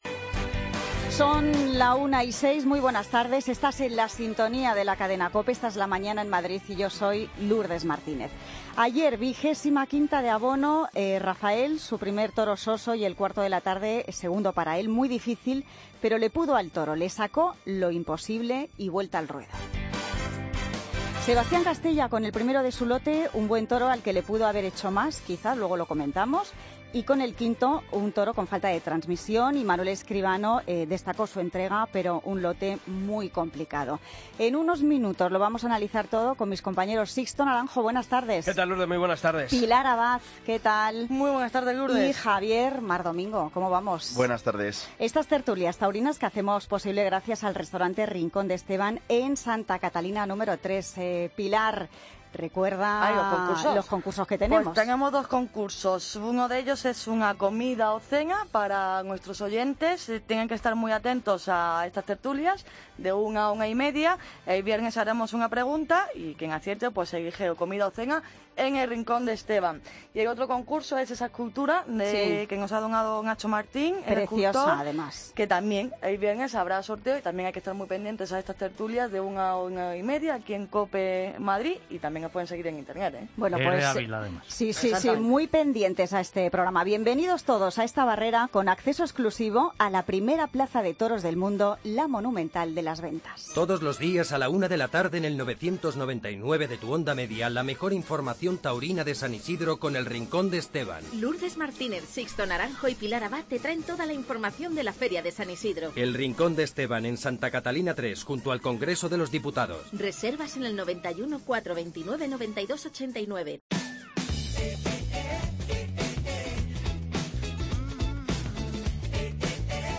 Tertulia Taurina Feria San Isidro COPE Madrid, martes 31 de mayo de 2016